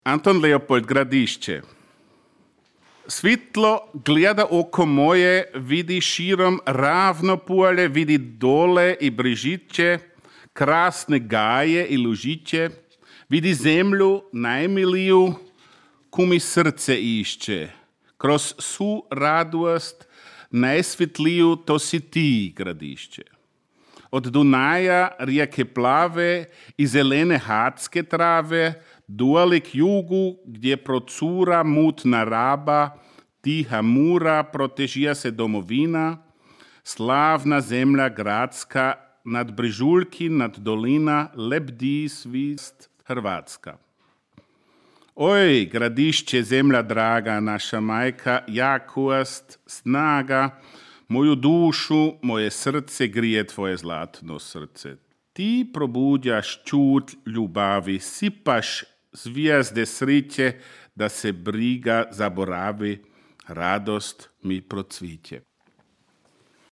čita: